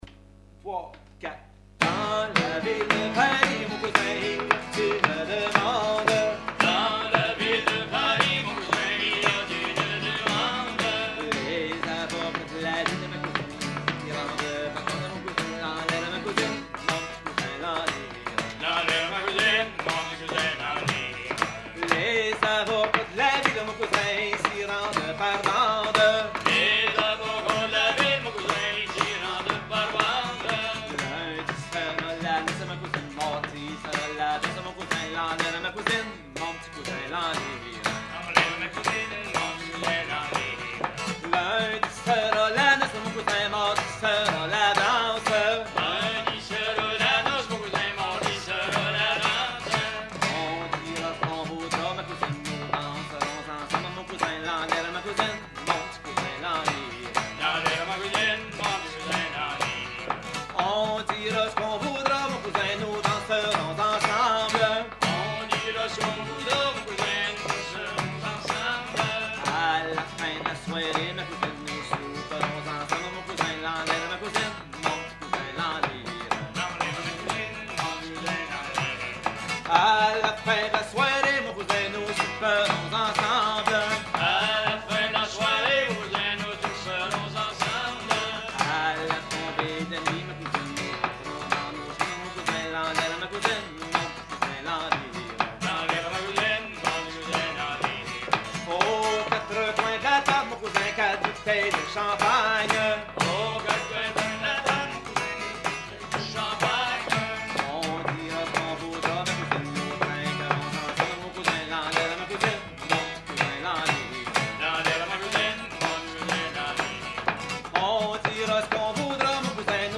Genre laisse
Concert à la ferme du Vasais
Pièce musicale inédite